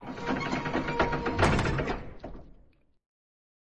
scpcb-godot/SFX/Door/Door2Close2.ogg at 648f0d0106a2afa42baa5e048925b8eac28285e8
Door2Close2.ogg